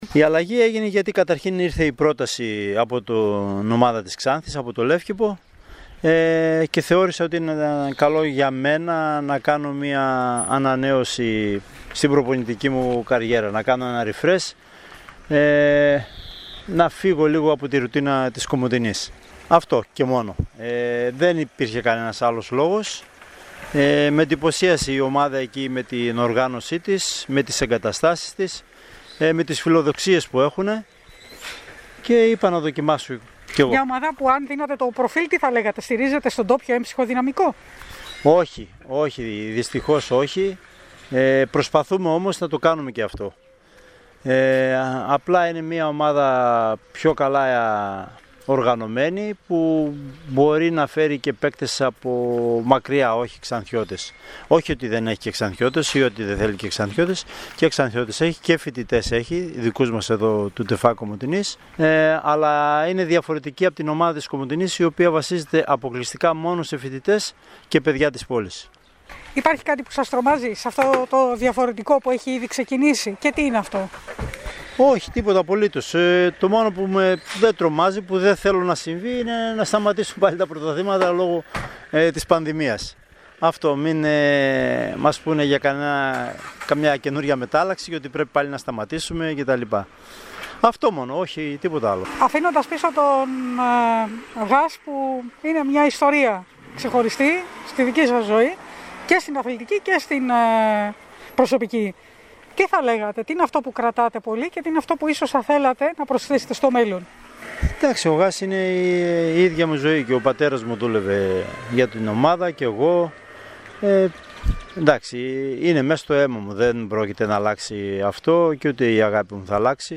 Για την αλλαγή στην προπονητική του καριέρα μίλησε στην ΕΡΤ Κομοτηνής και στην εκπομπή «Καθημερινές Ιστορίες»